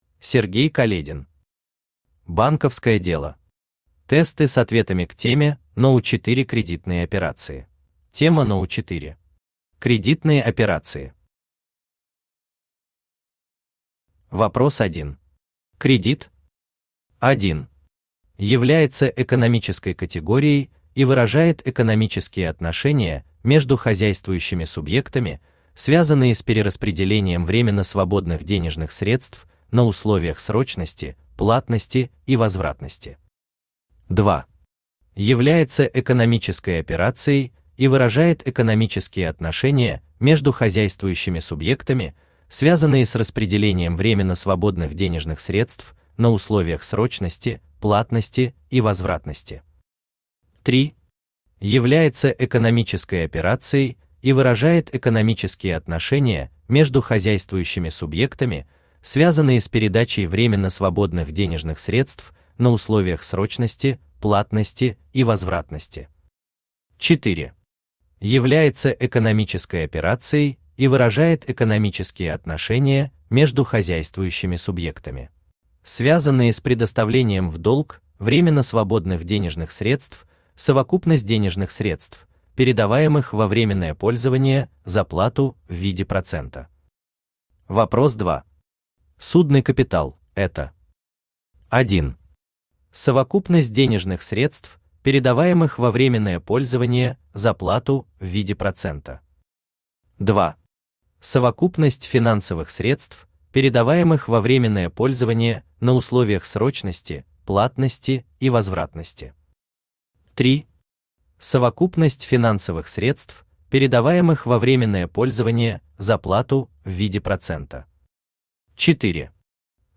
Аудиокнига Банковское дело. Тесты с ответами к теме № 4 «Кредитные операции» | Библиотека аудиокниг
Тесты с ответами к теме № 4 «Кредитные операции» Автор Сергей Каледин Читает аудиокнигу Авточтец ЛитРес.